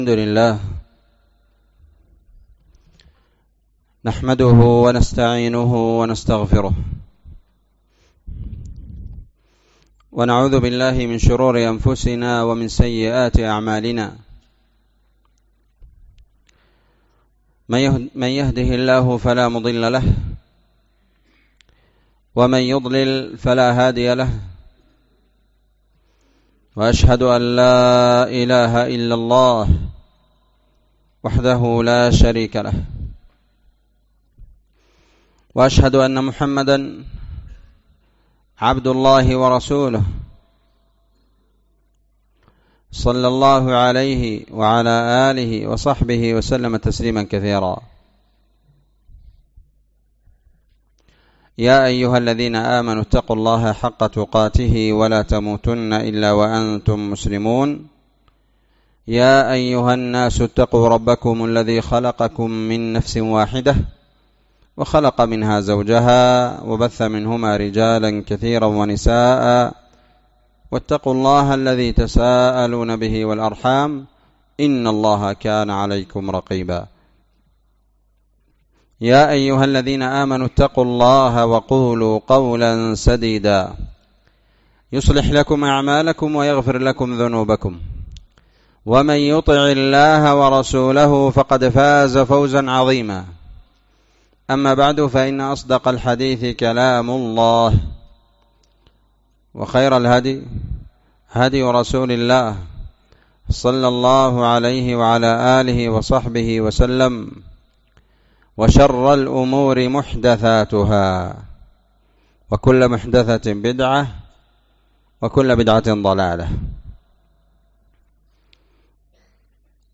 محاضرة في مدينة التربة ٢٤ ربيع الآخر ١٤٤٤